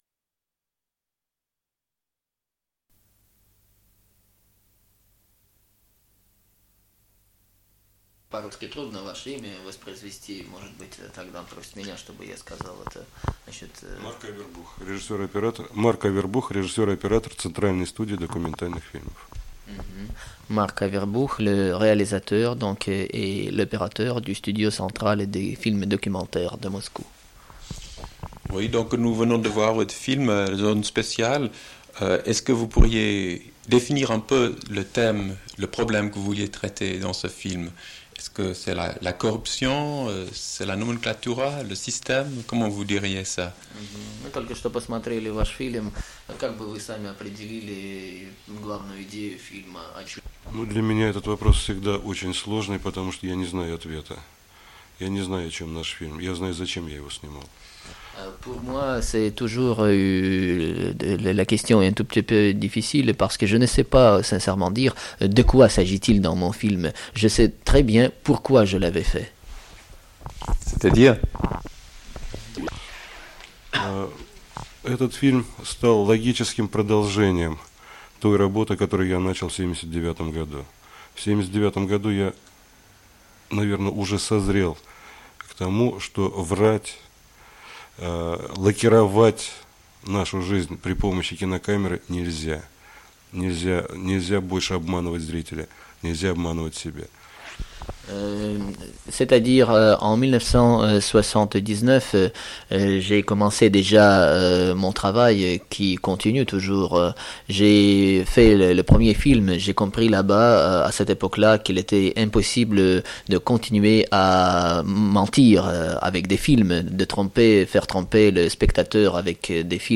Interview
Une cassette audio